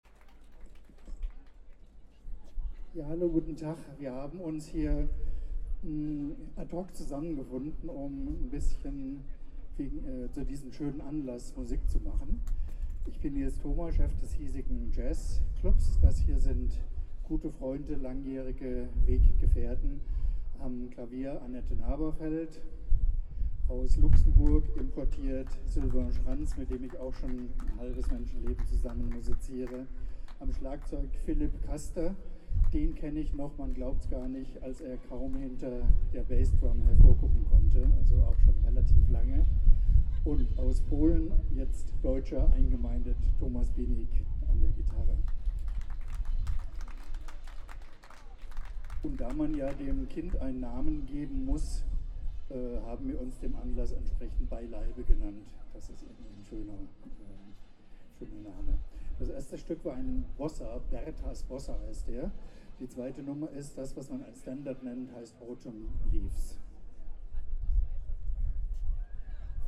02 - Ansage.mp3